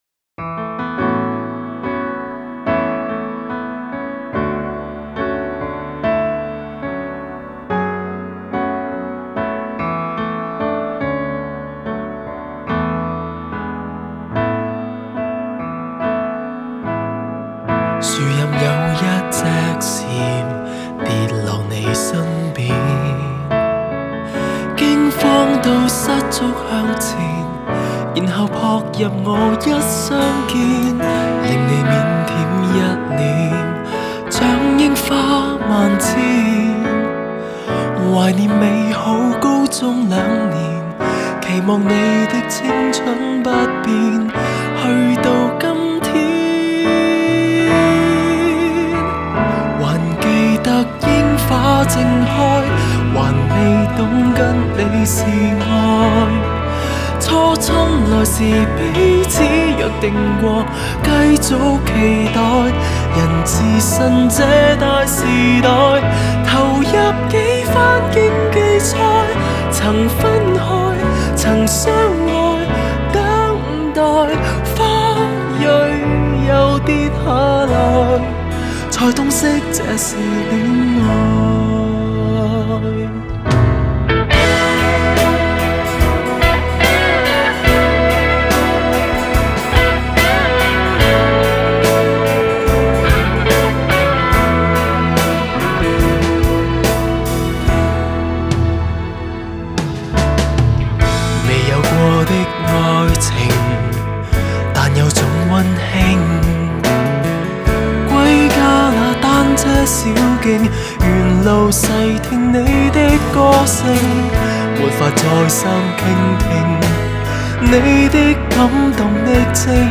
Ps：在线试听为压缩音质节选，体验无损音质请下载完整版 树荫有一只蝉，跌落你身边。